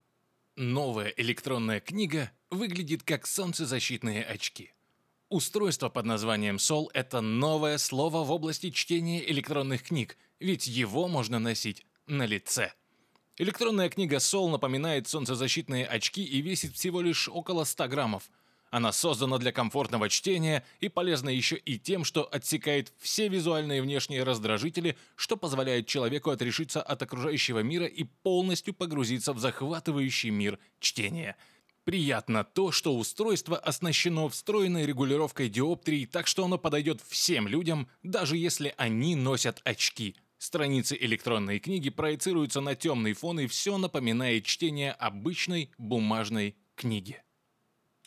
Муж, Рекламный ролик/Средний
Работаю в Reaper'е, микрофон - Fifine AM8 (динамический, проверенный временем и тоннами текста)